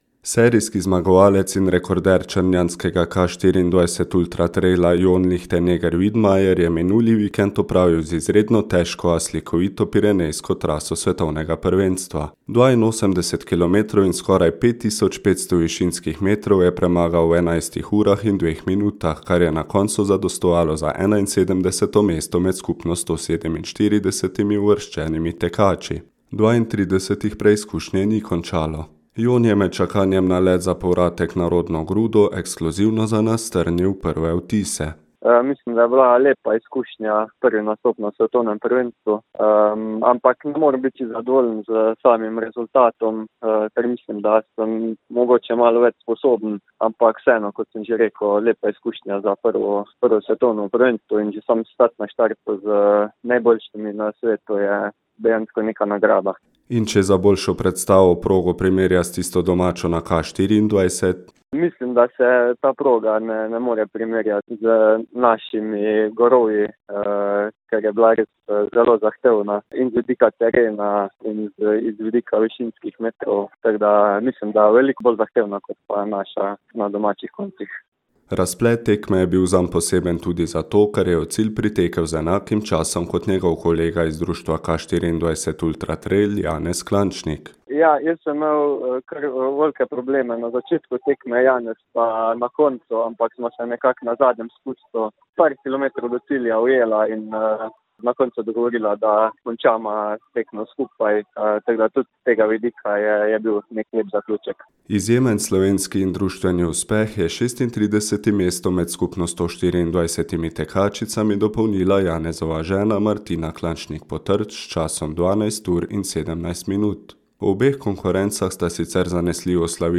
med čakanjem na let za povratek na rodno grudo ekskluzivno za nas strnil prve vtise in za boljšo predstavo progo primerjal s tisto domačo na K24.